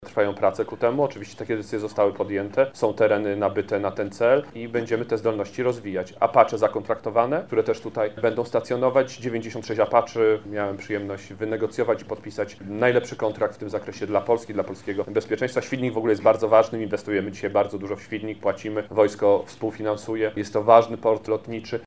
Władysław Kosiniak-Kamysz– mówi wicepremier i Minister Obrony Narodowej Władysław Kosiniak-Kamysz.